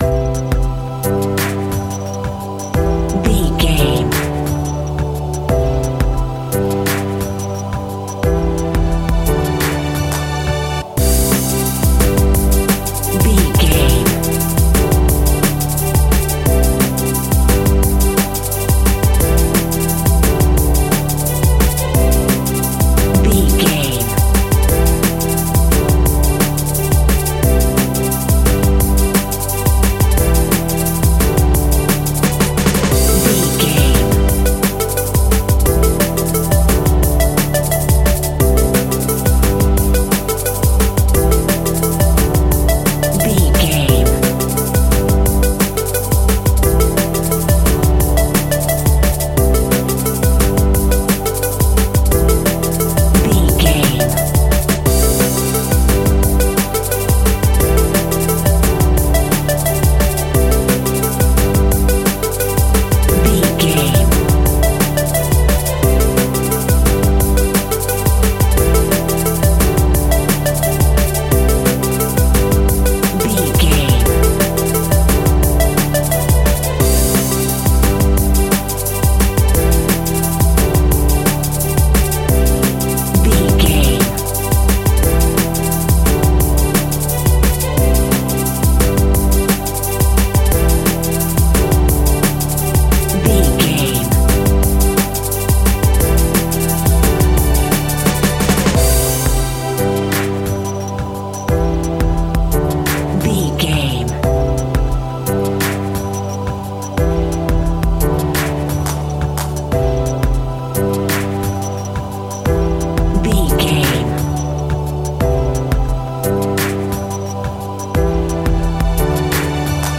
Ionian/Major
Fast
groovy
uplifting
bouncy
futuristic
electronic
sub bass
synth leads